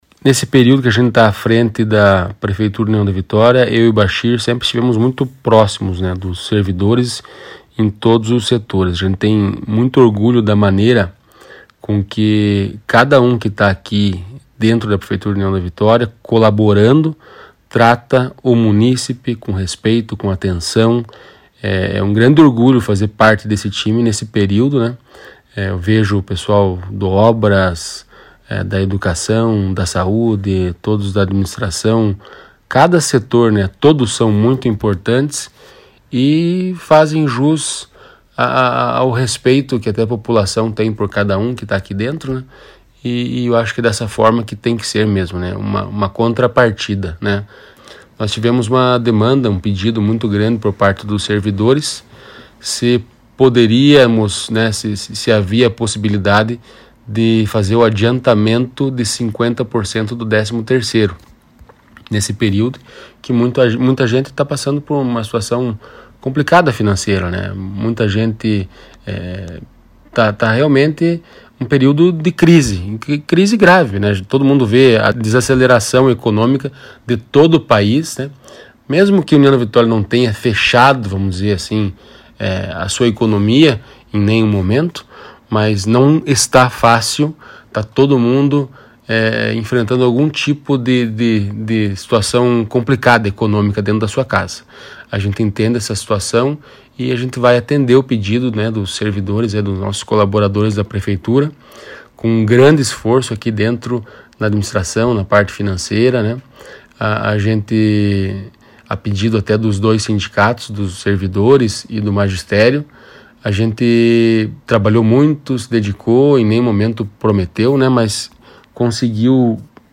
SANTIN-ROVEDA-FALÇA-DO-PAGAMENTO-DA-METADE-DO-13º-SALA´RIO-AOS-SERVIDORES-NESTA-SEXTA-FEIRA-DIA-26.mp3